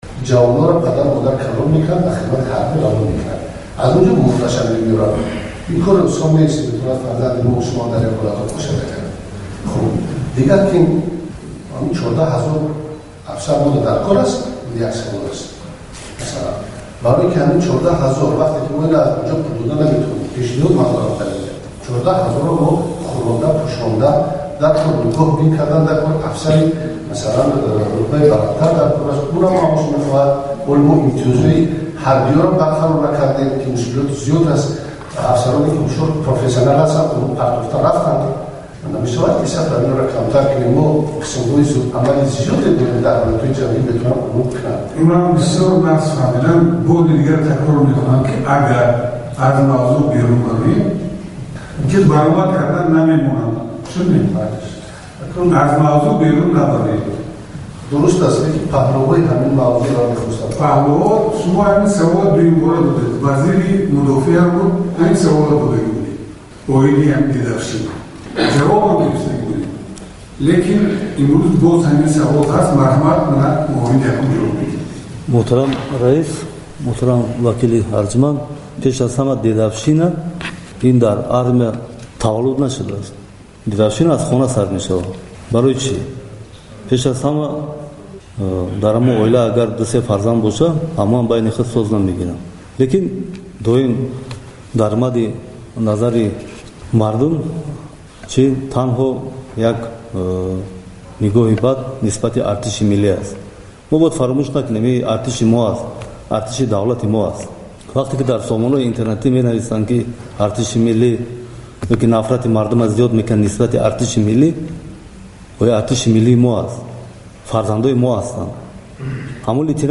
Аз ҷумла, Саидҷаъфар Усмонзода, вакил ва раиси Ҳизби демократ, рӯзи 28-уми апрел дар ҷаласаи порлумон аз муовини аввали вазири дифоъ, Эмомалӣ Собирзода, хост, масъаларо шарҳ диҳад.
Дар порлумон "зӯргӯӣ" дар артиши Тоҷикистонро баҳс карданд